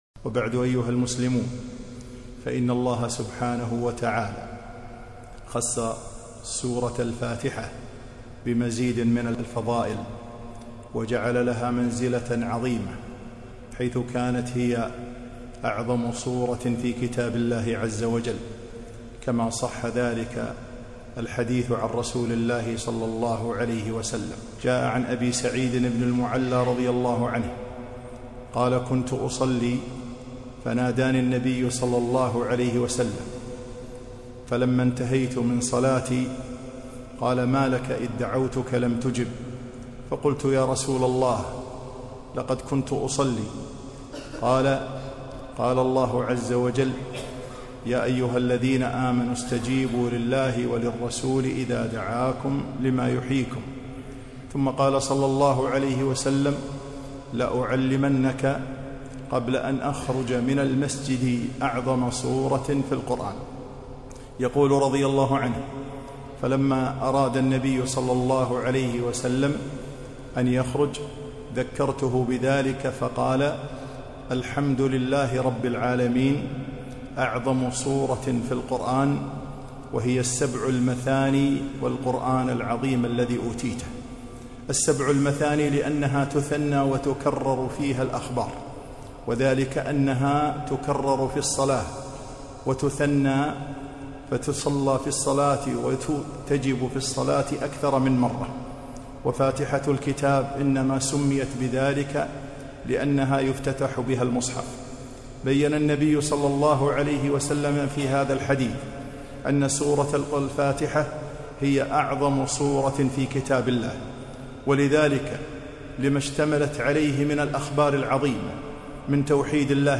خطبة - فضائل الفاتحة